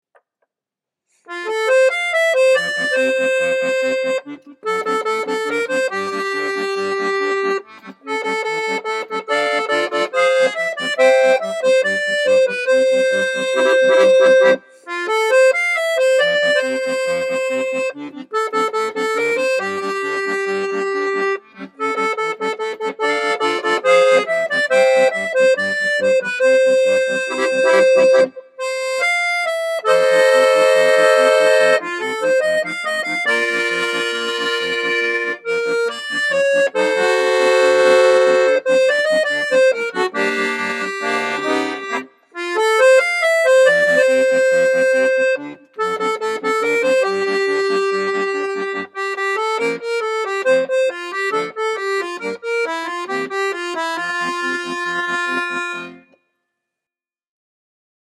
Der Refrain käme gerade im Anschluss an meinen Schluss, ich habe ihn ausgelassen, da es sonst zu leicht geworden wäre.